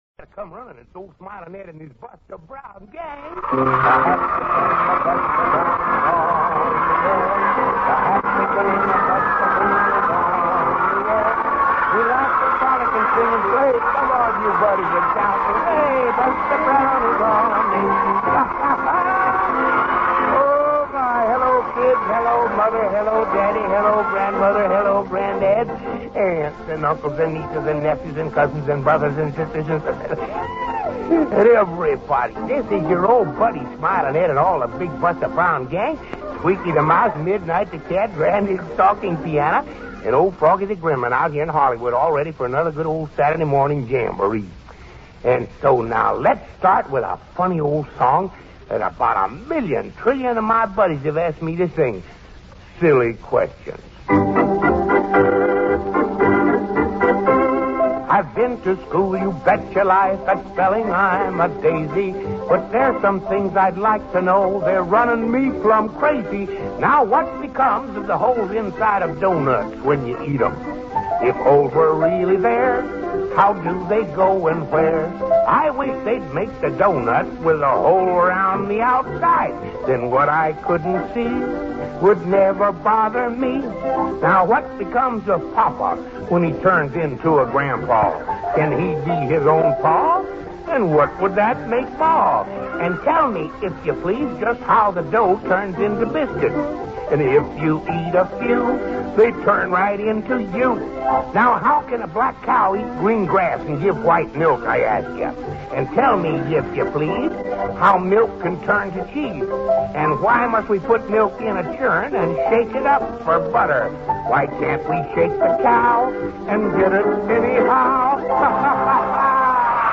A Buster Brown radio series began in 1943 with Smilin' Ed McConne